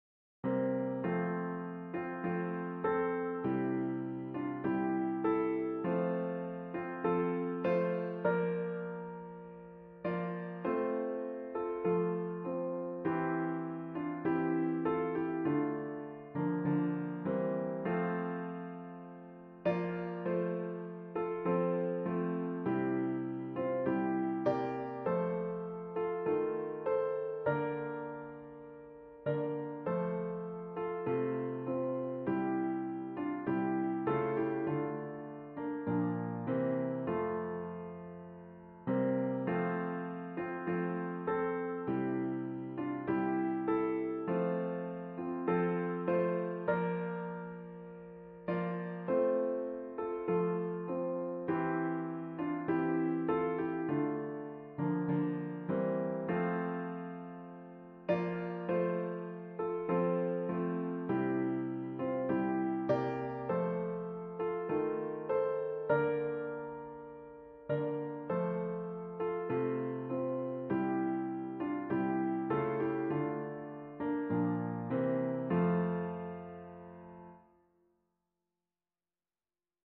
traditional Scottish melody
for piano